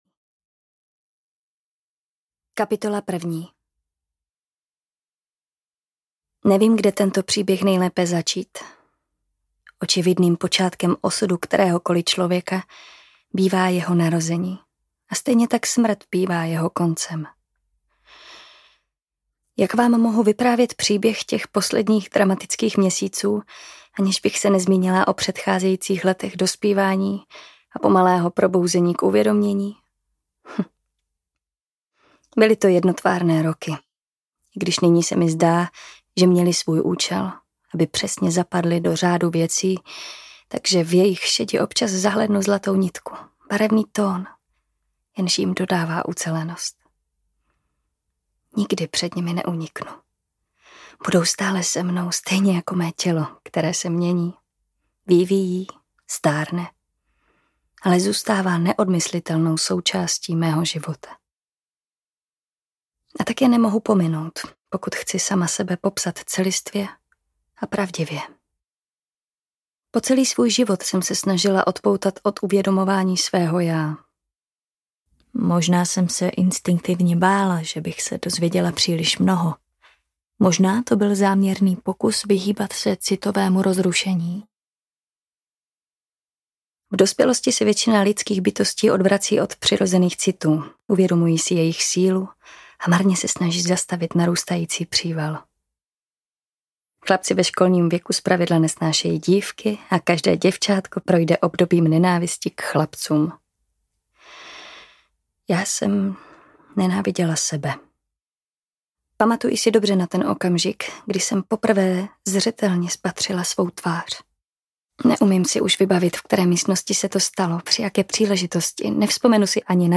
Černý panter audiokniha
Ukázka z knihy